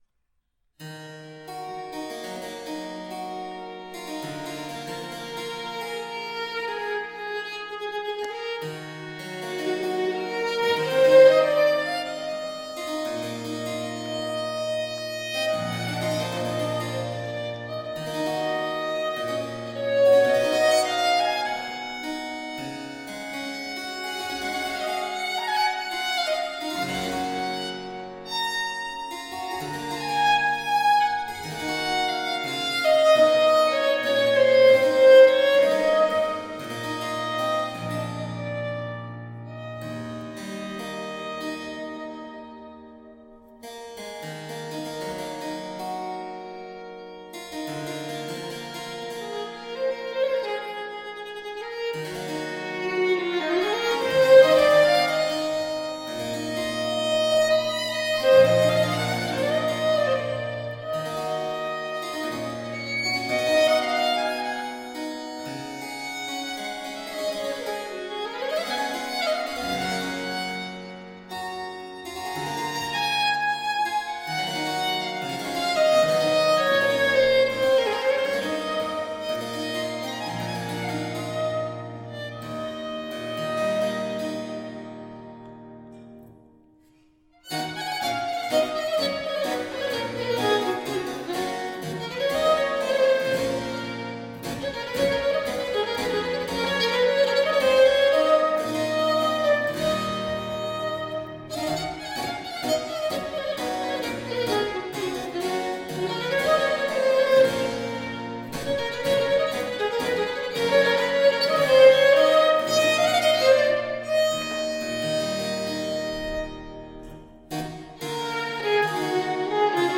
Small baroque chamber ensemble.
Tagged as: Classical, Chamber Music, Baroque, Instrumental